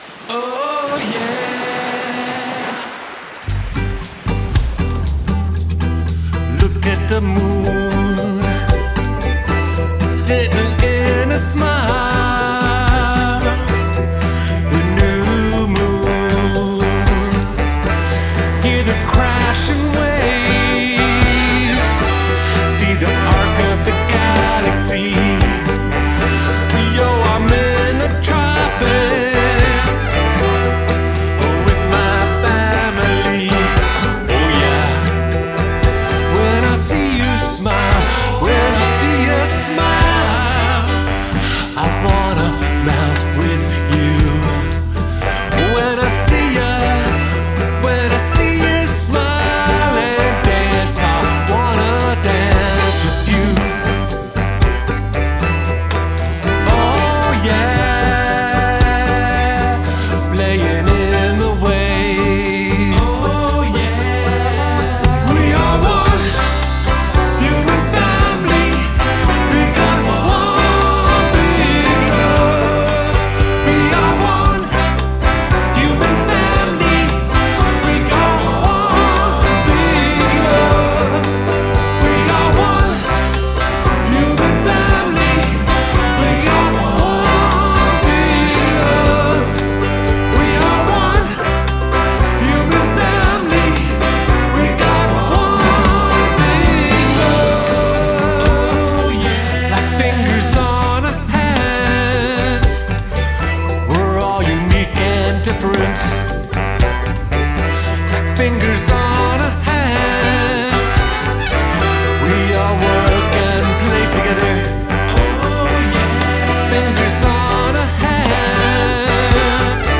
vocals, acoustic guitars, organ, clavinet, ukulele
drums, percussion
trumpet
trombone
alto saxophone
baritone saxophone, double second pan
lead tenor pan
backing vocals